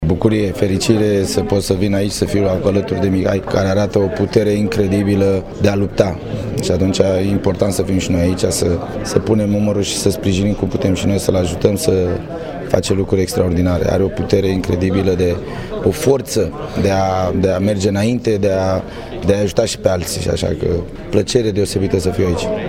Despre acțiunea dedicată lui Mihai Neșu a vorbit și Gheorghe Hagi:
F5-Hagi-despre-eveniment.mp3